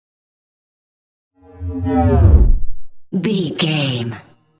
Deep whoosh pass by
Sound Effects
dark
tension
whoosh